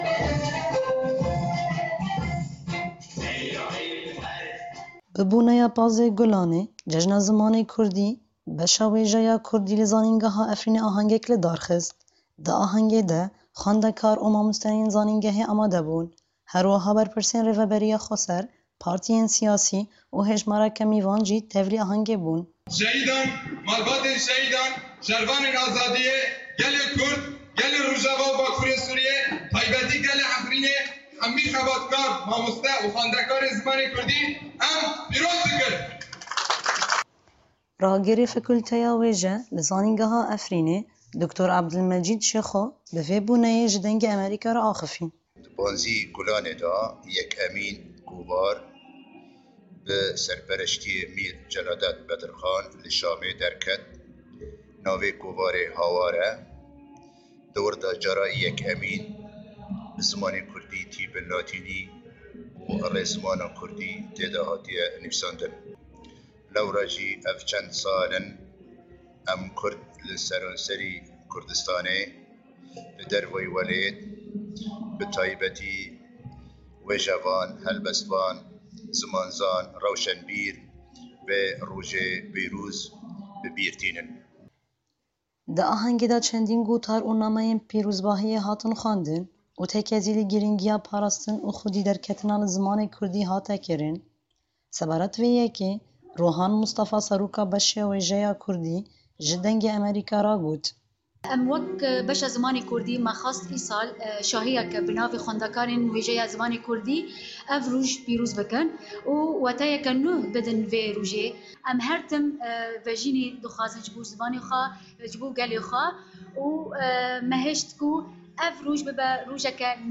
Bi boneya 15’ê Gulanê Cejna Zimanê Kurdî, beşê wêjeya Kurdî li zanîngeha Efrînê ahengek li dar xist.
Merasîm bi xwendina helbestan ji alîyê xwendekarên zanîngehê ve dewam kir, herwiha şano, mûzîk û stran hatin pêşandan, û rastî coş û kêfxweşîya beşdaran hat.